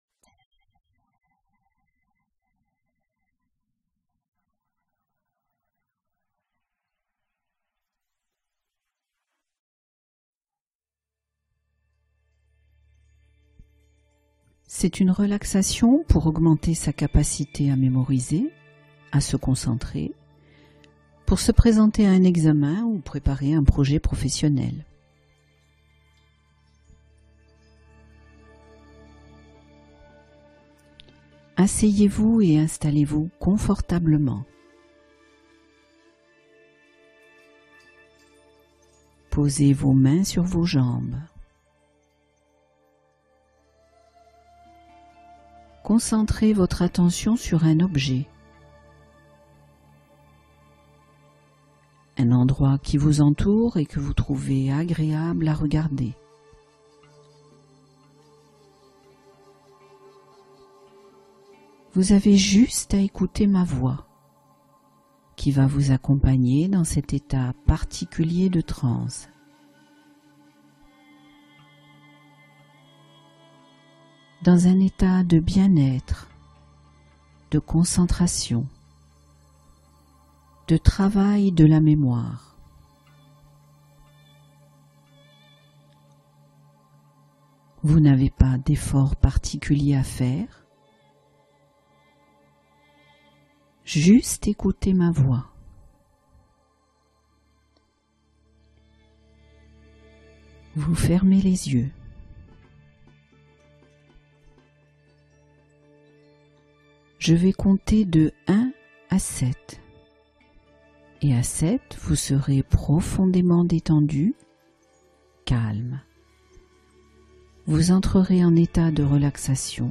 Se libérer de la dépendance au tabac — Hypnose d’amorce au changement